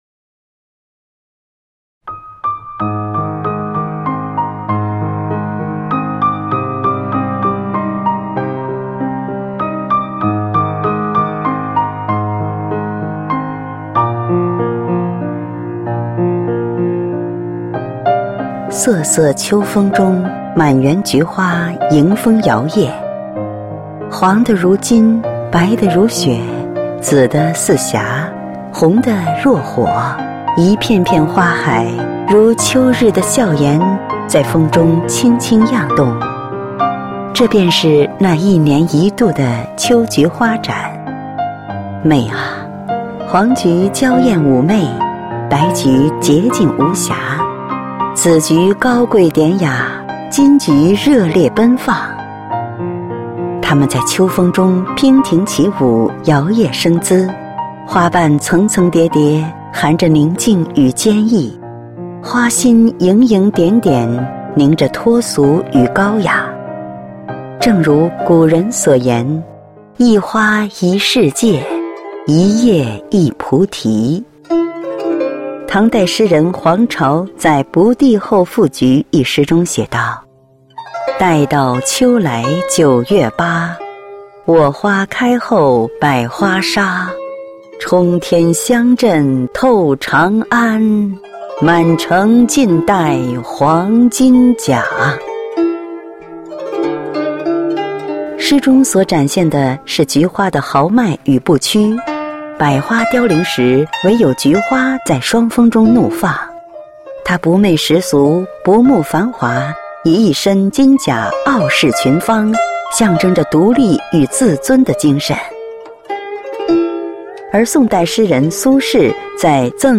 配樂散文朗讀（音頻）：秋菊（MP3）